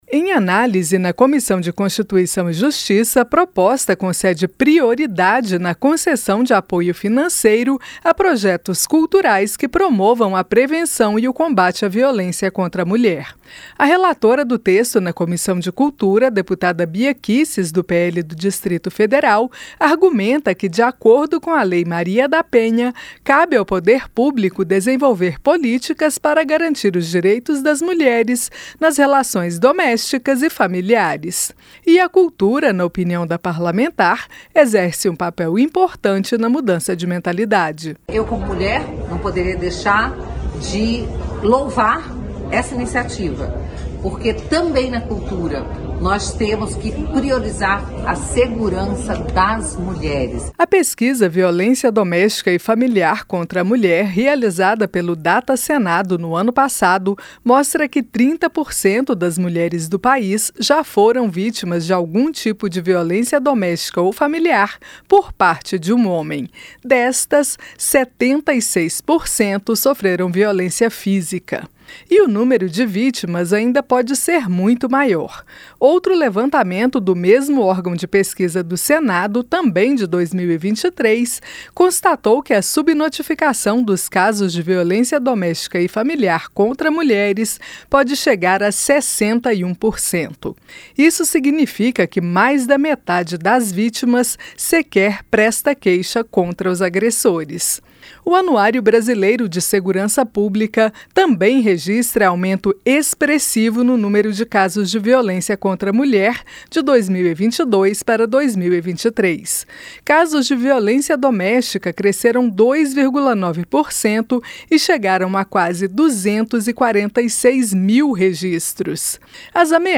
PROPOSTA QUE ESTÁ SENDO EXAMINADA NA CÂMARA PRIORIZA PROJETOS CULTURAIS QUE ESTIMULEM O COMBATE À VIOLÊNCIA CONTRA A MULHER. A REPORTAGEM